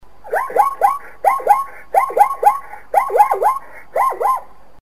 Звуки зебры
Звук дикой зебры в ночной тишине